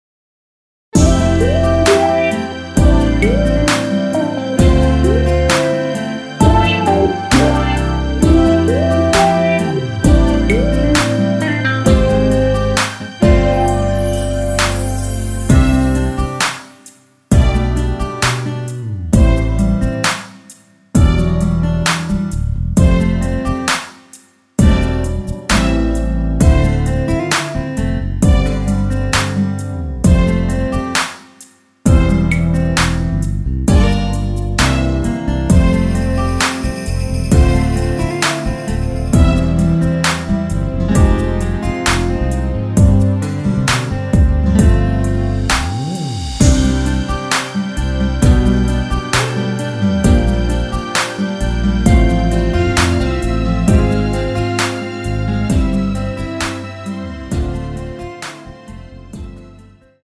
전주후 바쁜 하루 중에도…으로 진행이 됩니다.
앞부분30초, 뒷부분30초씩 편집해서 올려 드리고 있습니다.